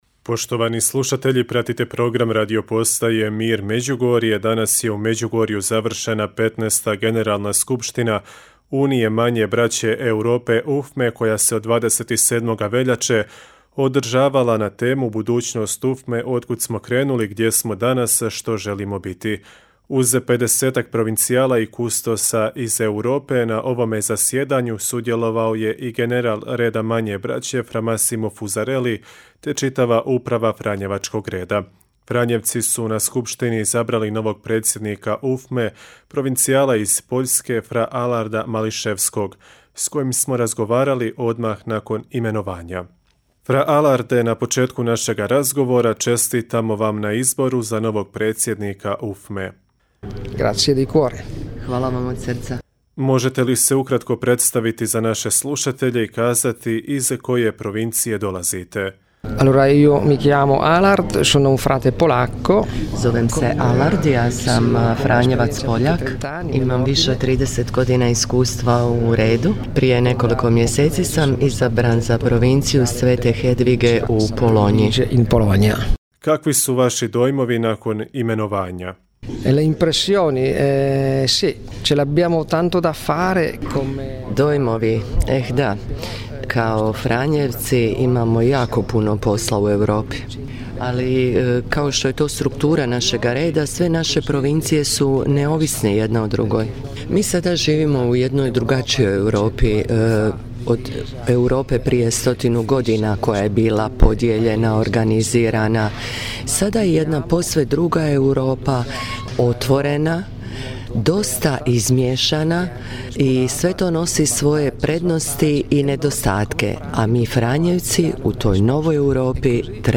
S njim smo razgovarali odmah nakon imenovanja.